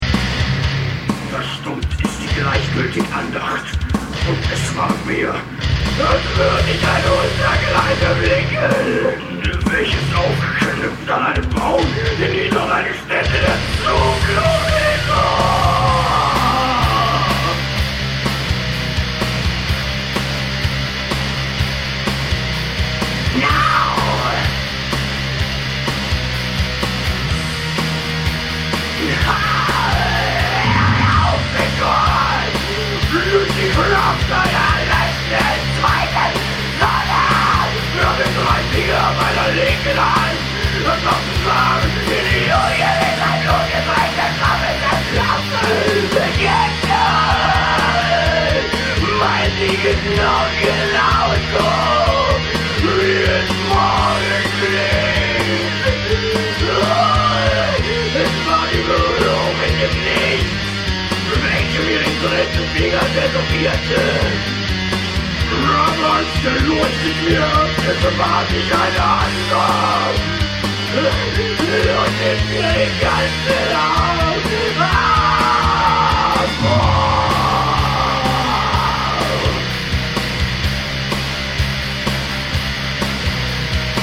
All the sound files below are fragments of the actual songs.